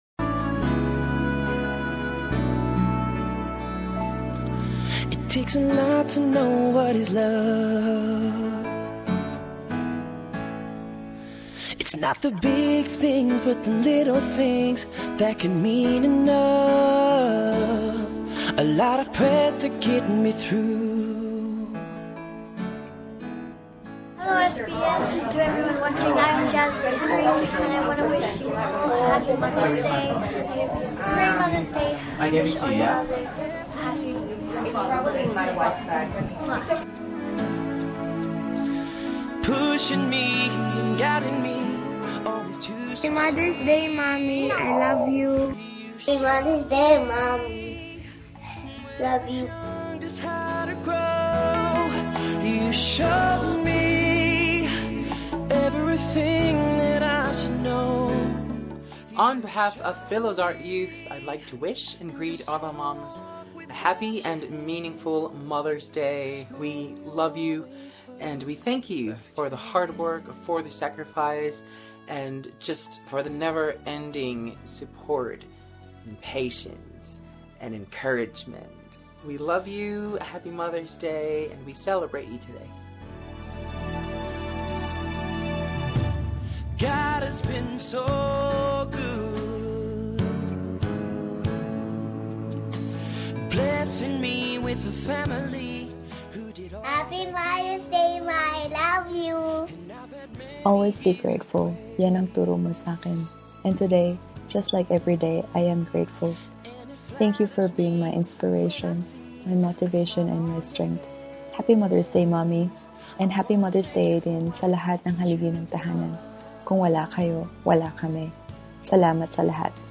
Several happy mothers' day greetings from some of the children who had been part of SBS Filipino.